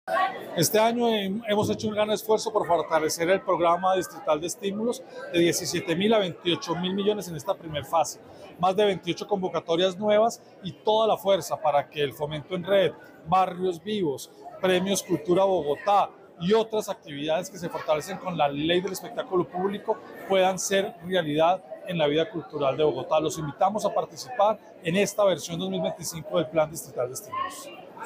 Con relación a las expectativas del Programa de Estímulos del presente año, el Secretario de Cultura, Santiago Trujillo, explicó: